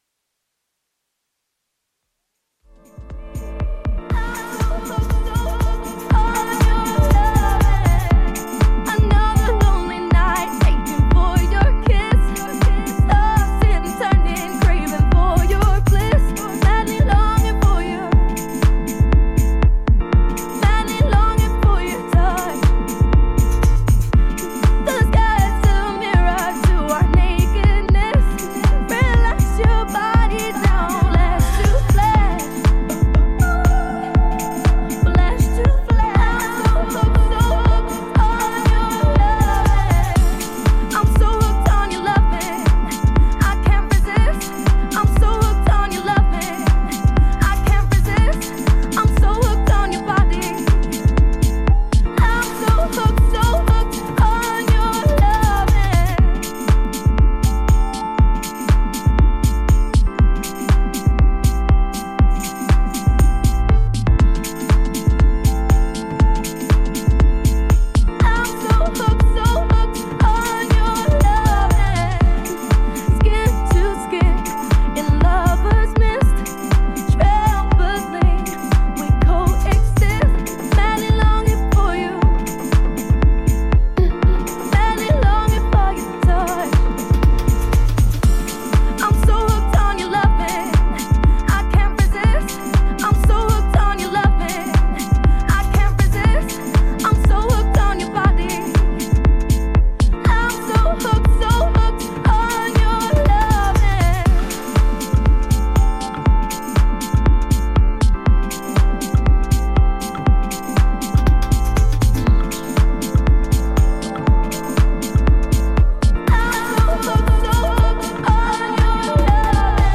ジャンル(スタイル) HOUSE / SOULFUL HOUSE